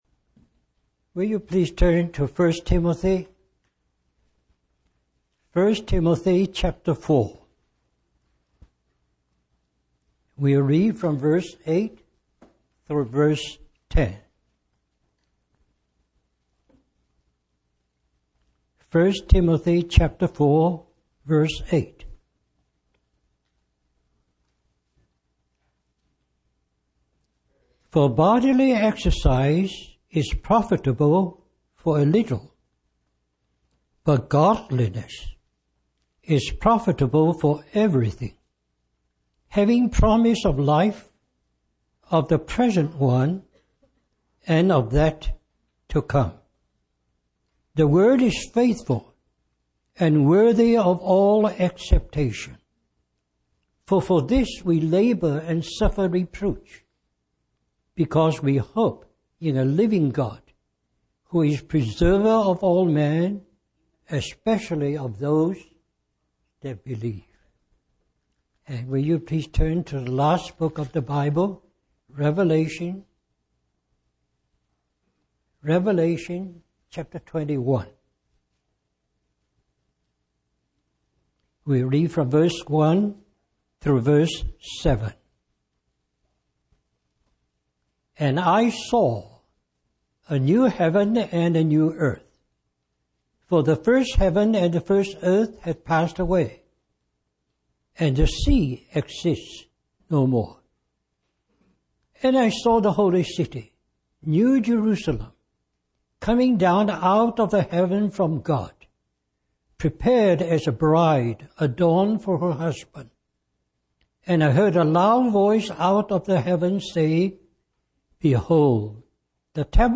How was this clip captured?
Richmond, Virginia, US